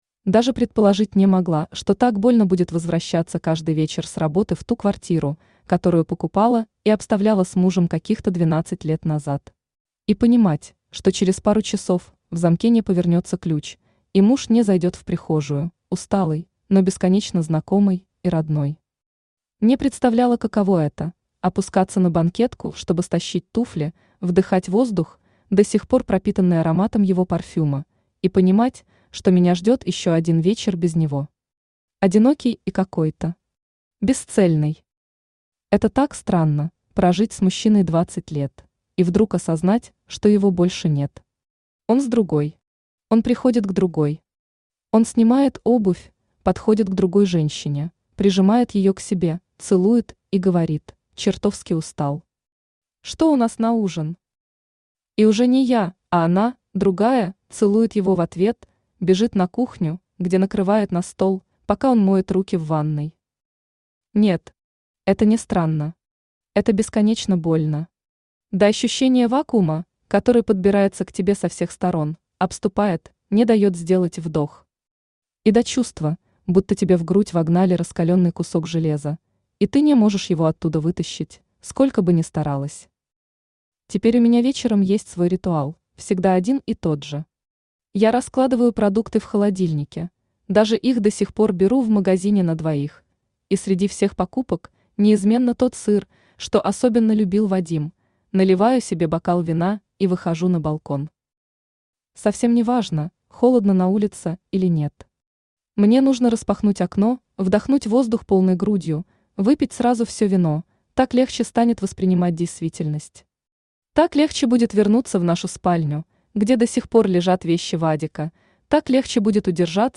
Aудиокнига Триггер Автор Полина Рей Читает аудиокнигу Авточтец ЛитРес.